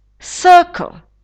circle [sə:kl]